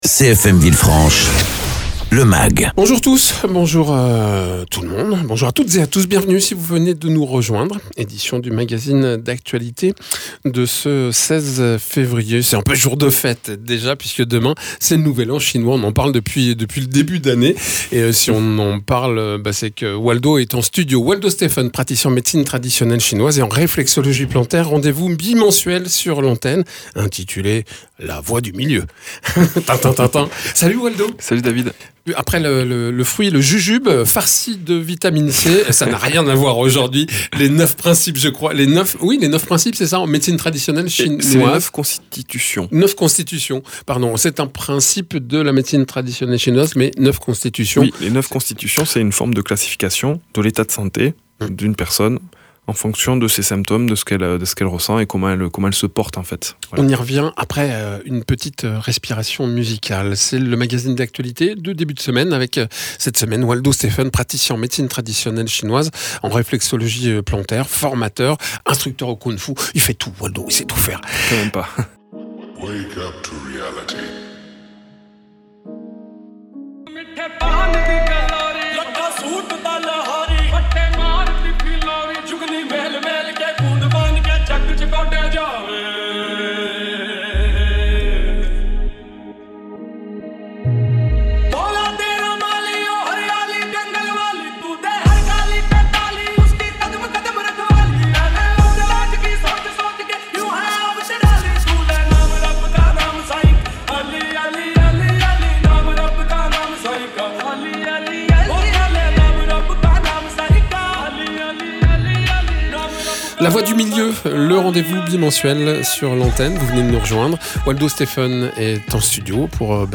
praticien en réflexologie plantaire et Médecine Traditionnelle Chinoise